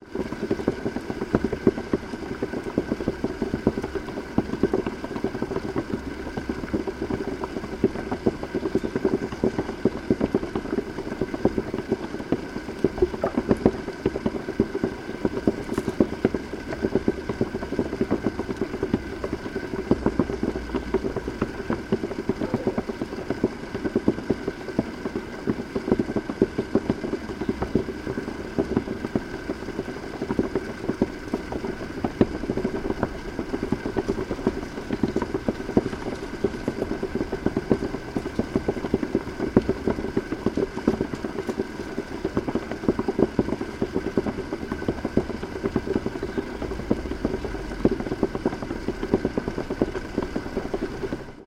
Шум парового увлажнителя воздуха